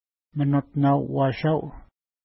Pronunciation: mənutna:w-wa:ʃa:w
Pronunciation